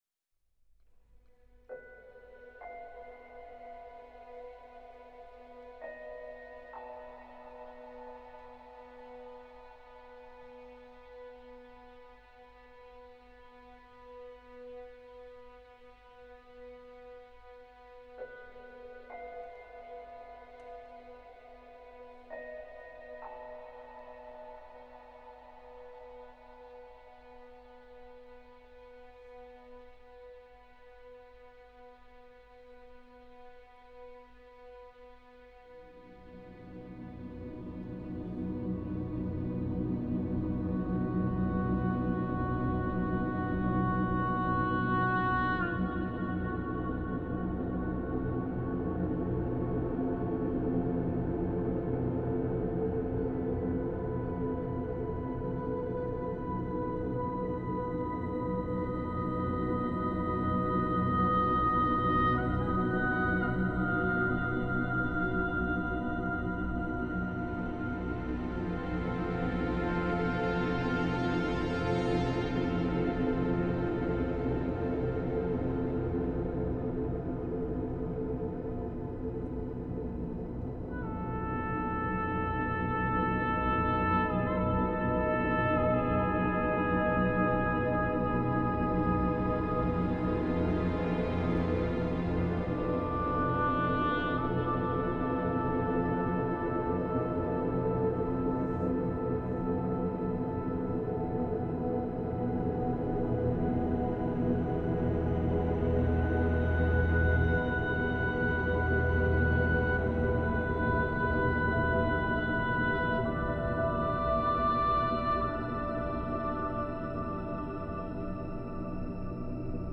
masterfully atmospheric